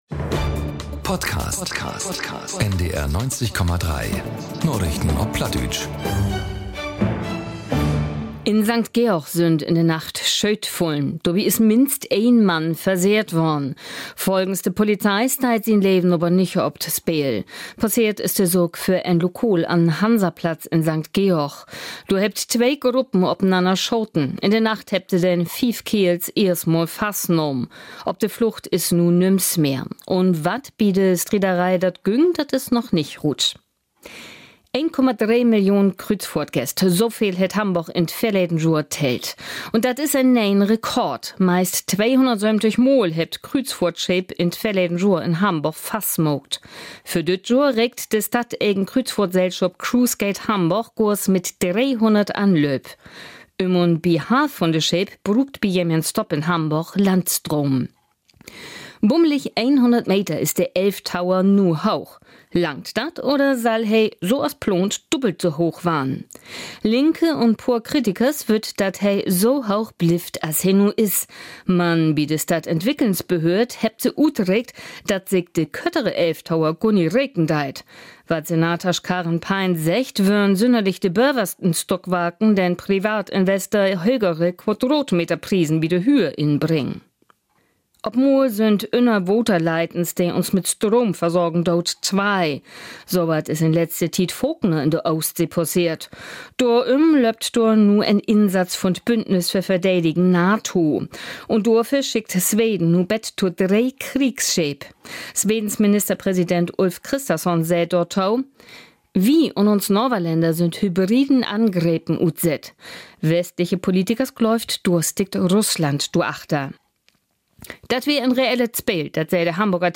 Ut Hamborg un de wiede Welt - vun maandaags bet sünnavends: Die aktuellen Nachrichten auf Plattdeutsch bei NDR 90,3.